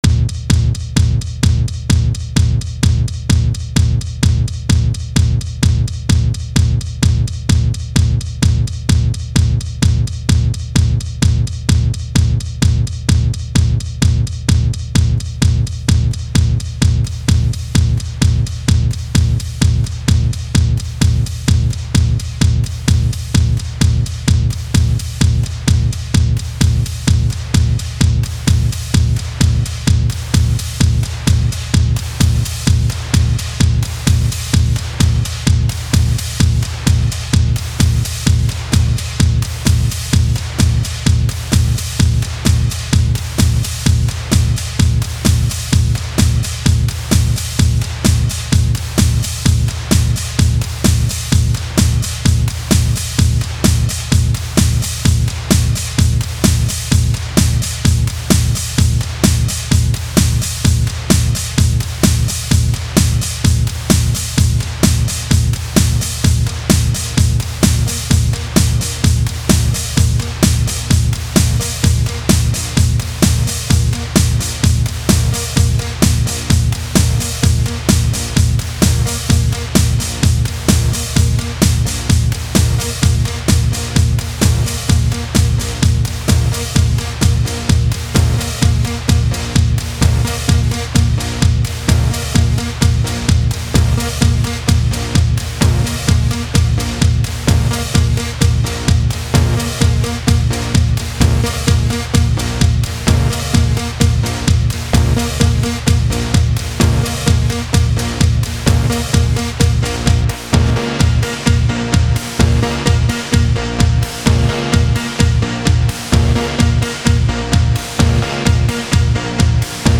الکترونیک